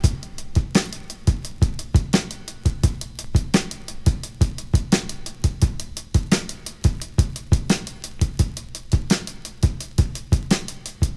• 86 Bpm Drum Loop D Key.wav
Free drum beat - kick tuned to the D note. Loudest frequency: 1270Hz
86-bpm-drum-loop-d-key-BZ4.wav